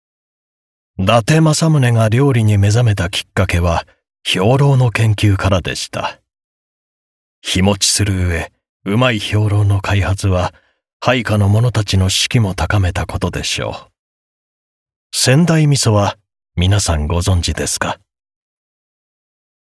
ボイスサンプル
• ナレーション：渋め、企業、ドキュメンタリー等